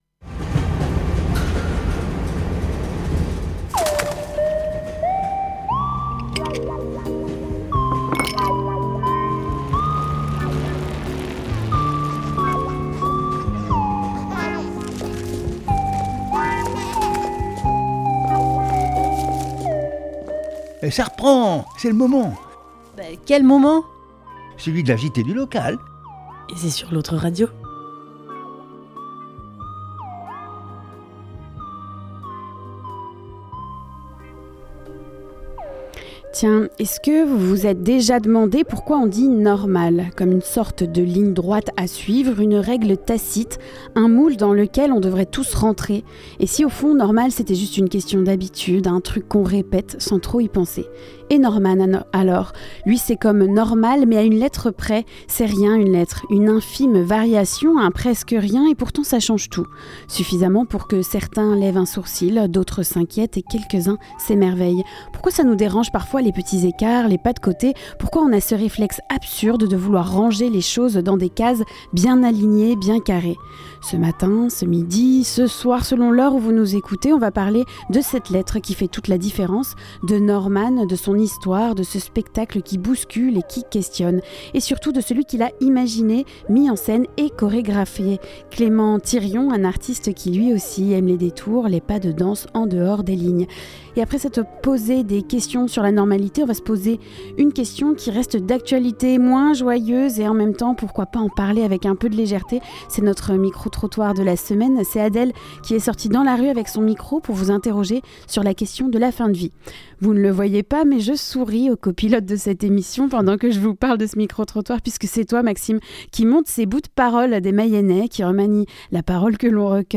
Le micro trottoir de la semaine